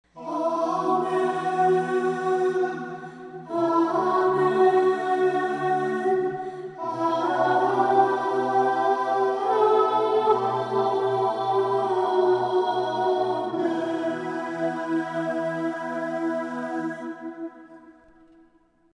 female vocals
Choral    Pop    Home